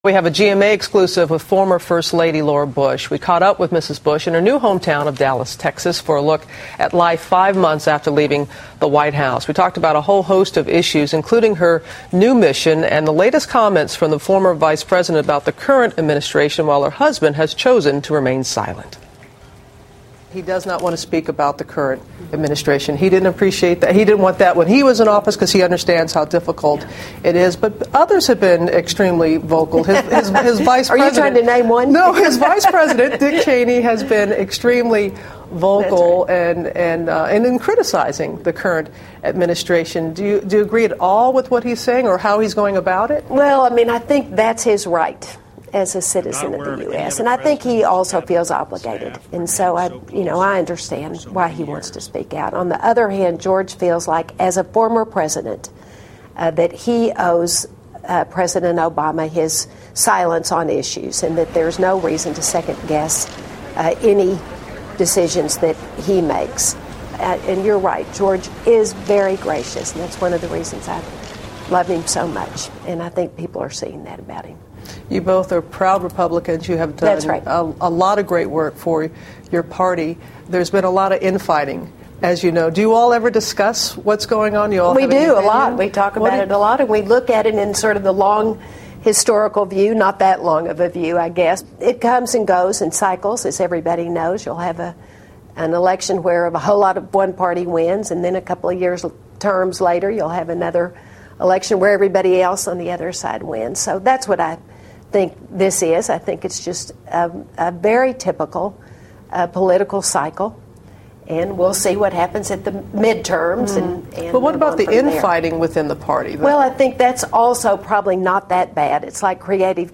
We have a GMA (Good morning America) exclusive of former First Lady Laura Bush. We caught up with Mrs. Bush in a new hometown of Dallas, Taxes for a look at life 5 months after leaving the White House. We talked about a whole host of issues, including her new mission and the latest comments from the former Vice President about the current Administration where her husband has chosen to remain silent.